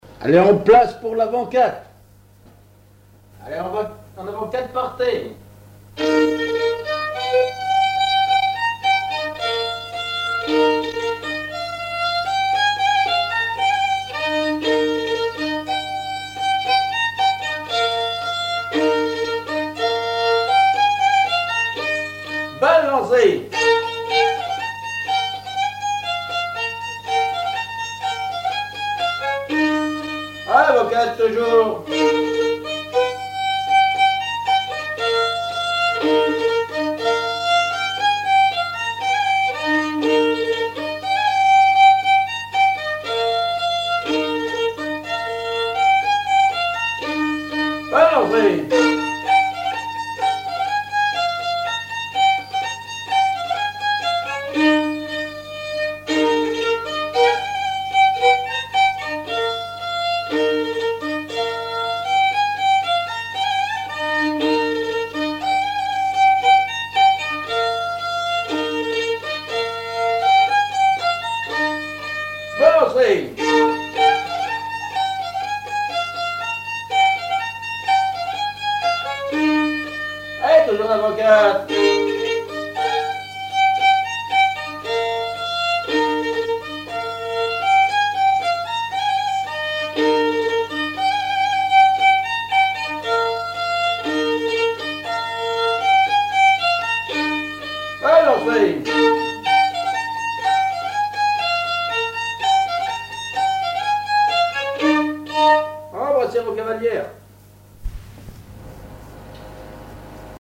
danse : quadrille : avant-quatre
Auto-enregistrement
Pièce musicale inédite